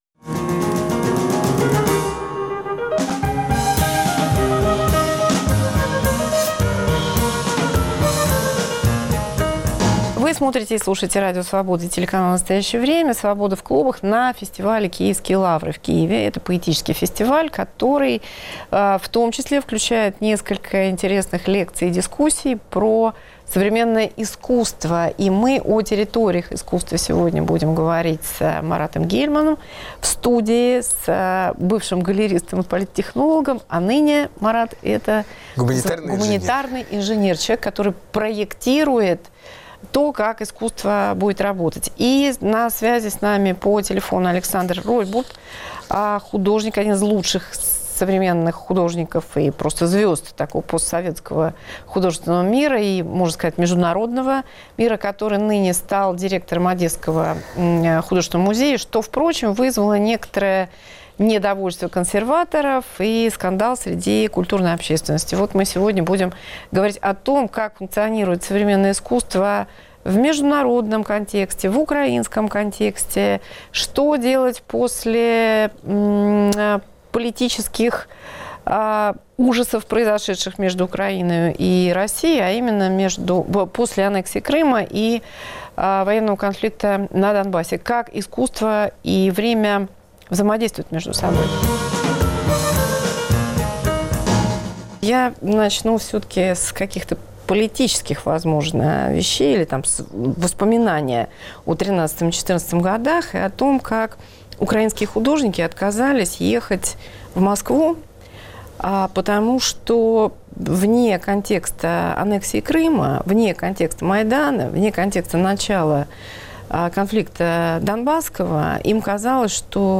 Как делать международные выставки и как жить художнику в эпоху гибридных войн? Арт-менеджеры Марат Гельман (Россия-Черногория) и Александр Ройтбурд (Киев-Одесса) на фестивале Киевские лавры